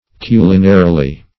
Search Result for " culinarily" : The Collaborative International Dictionary of English v.0.48: Culinarily \Cu"li*na*ri*ly\ (k?`l?-n?-r?-l?), adv. In the manner of a kitchen; in connection with a kitchen or cooking.
culinarily.mp3